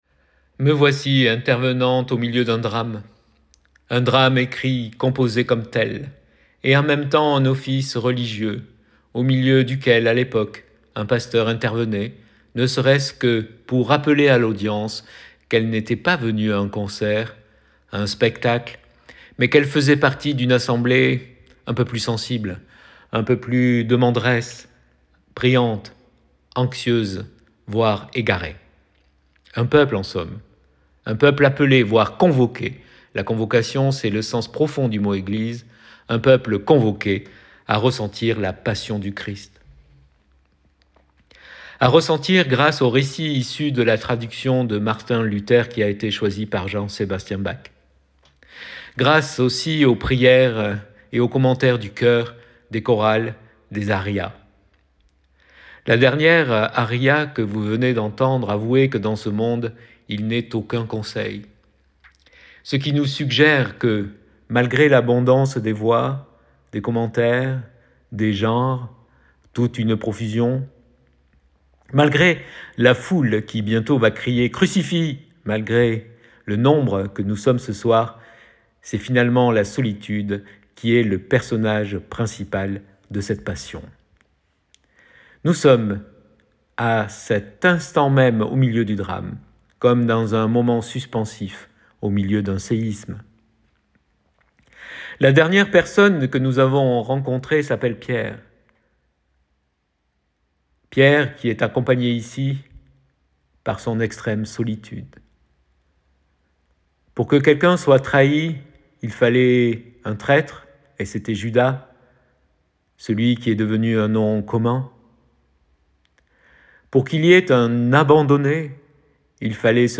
ÉVOCATION DU CONCERT LITURGIQUE DE PÂQUES DU CHOEUR DU TEMPLE DE PORT ROYAL (27 MARS 2024)